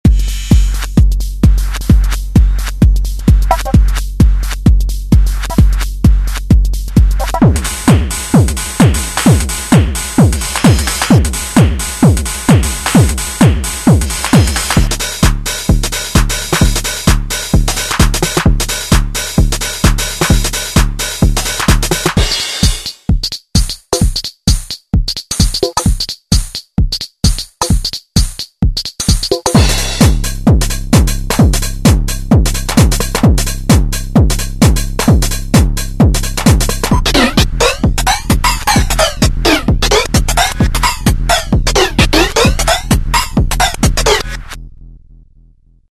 Category: Sound FX   Right: Personal
Tags: Sound Effects JoMoX Sounds JoMoX XBase AirBase